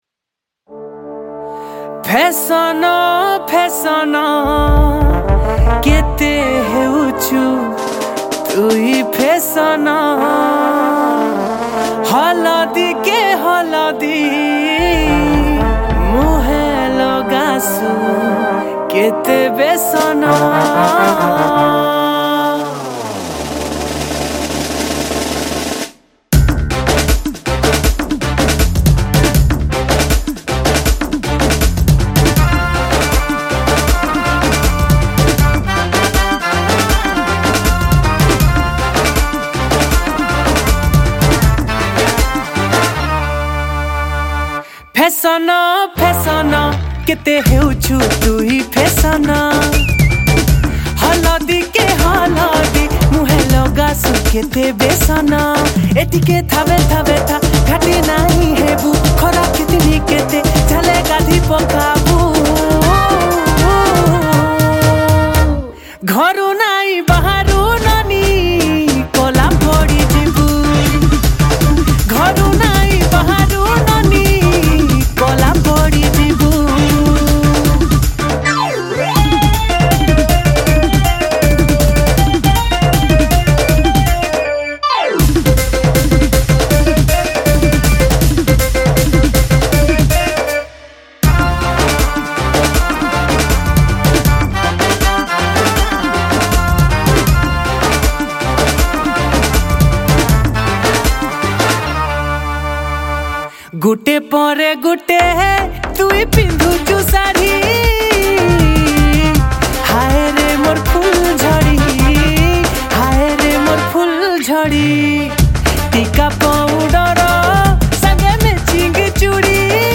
Koraputia Song